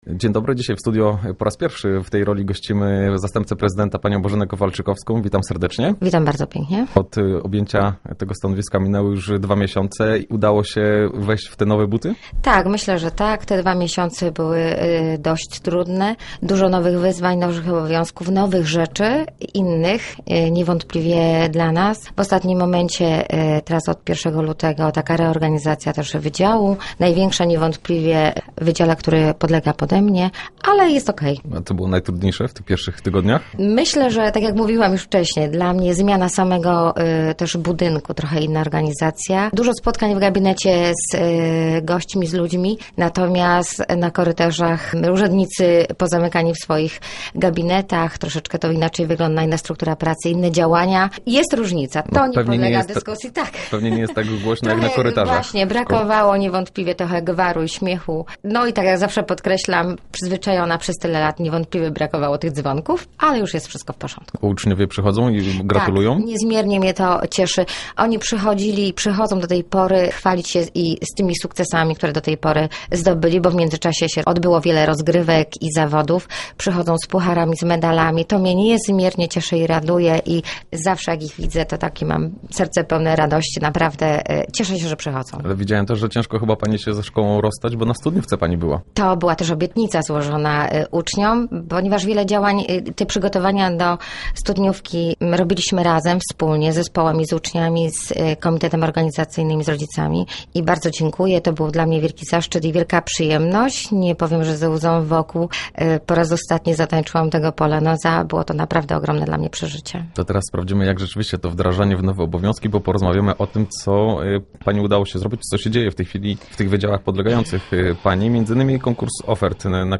Do końca tygodnia poszczególne organizacje otrzymają informacje, kto i ile pieniędzy otrzyma. To jeden z tematów, o których mówi Bożena Kowalczykowska, zastępca prezydenta Głogowa.